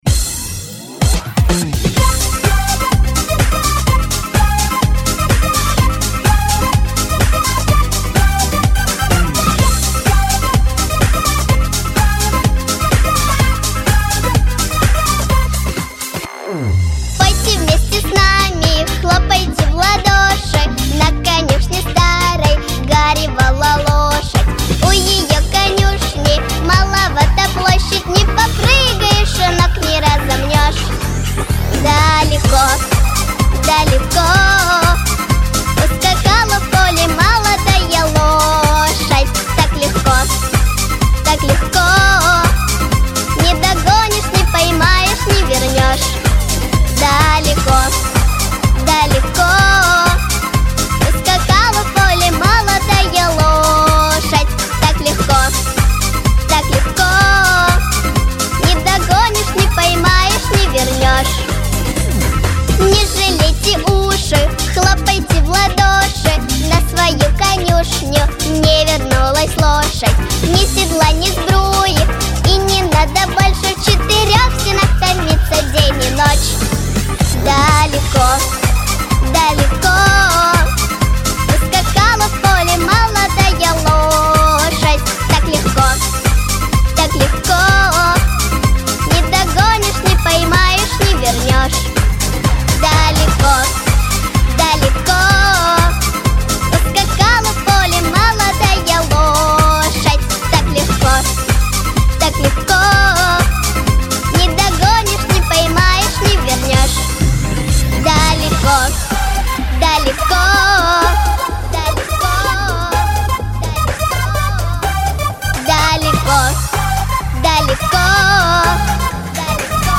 • Категория: Детские песни
детская дискотека